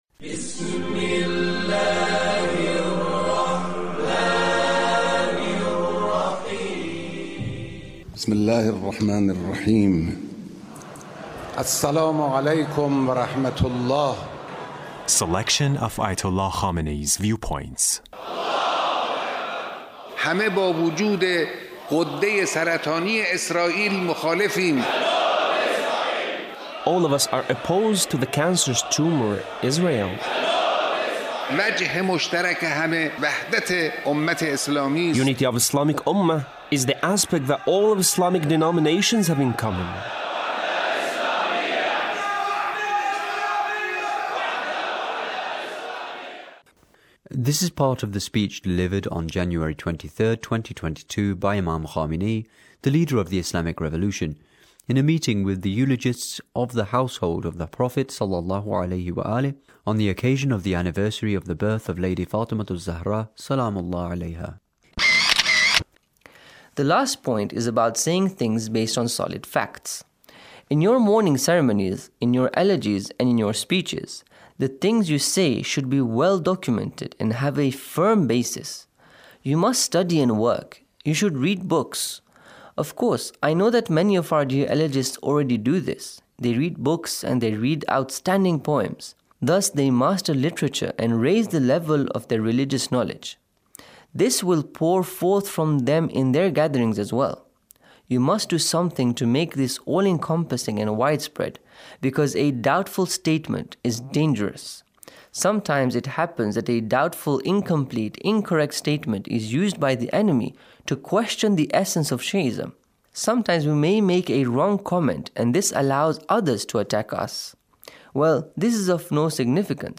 Leader's Speech on a Gathering with eulogists of the Household of the Prophet